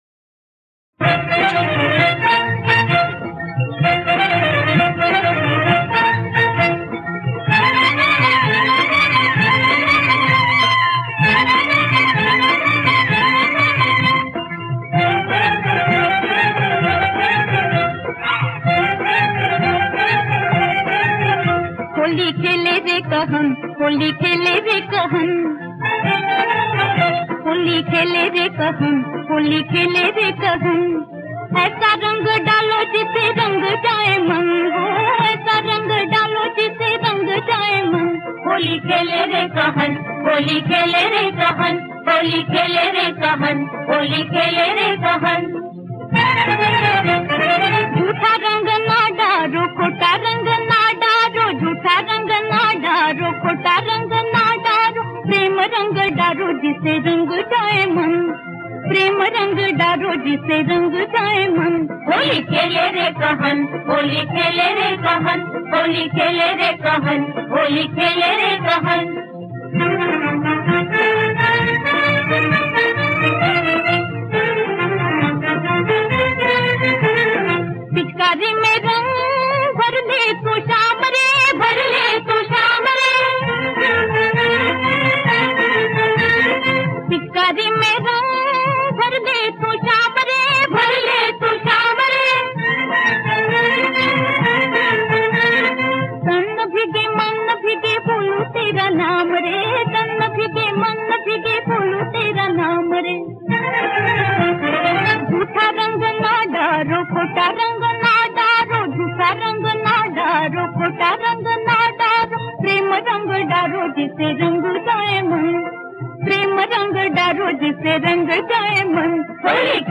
Bollywood Songs